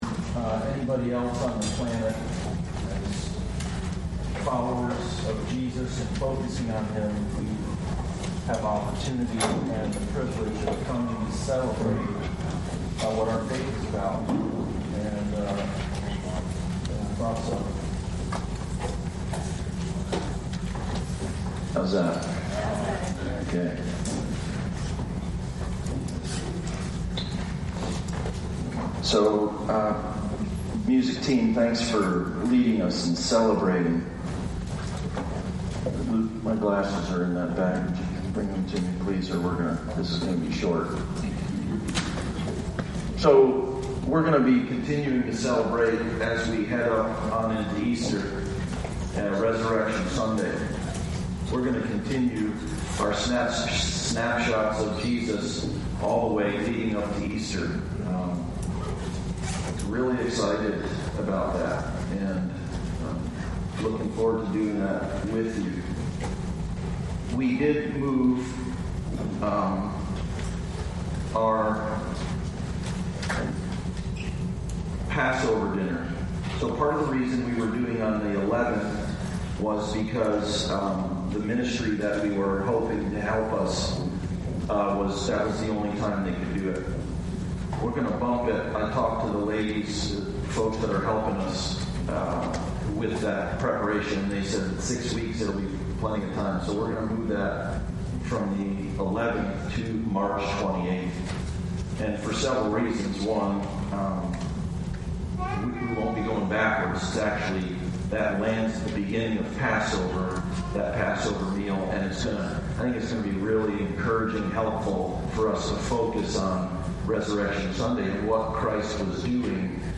Passage: Luke 16:19-31 Service Type: Sunday Service